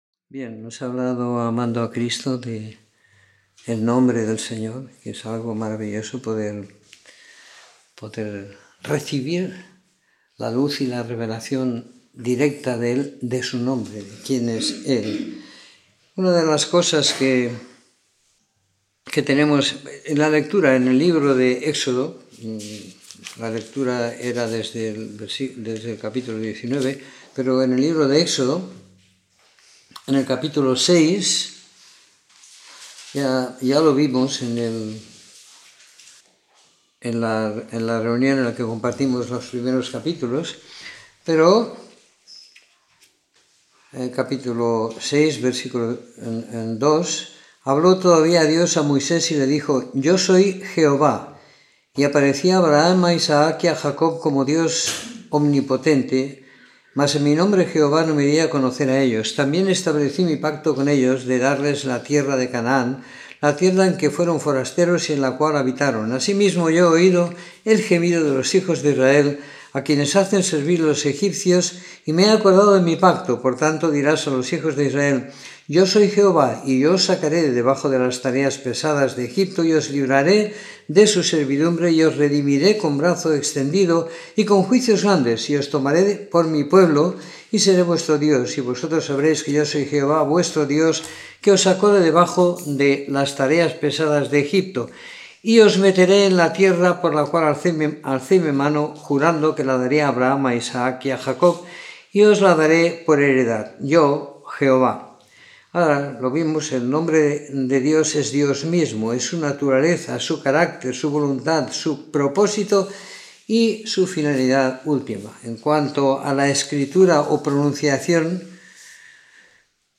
Comentario en el libro de Éxodo del capítulo 19 al 40 siguiendo la lectura programada para cada semana del año que tenemos en la congregación en Sant Pere de Ribes.